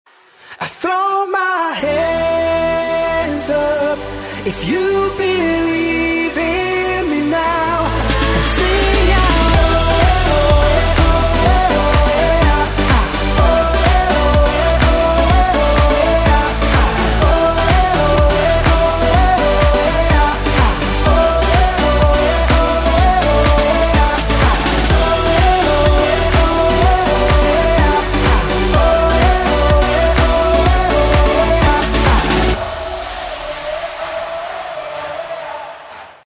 • Категория:R&B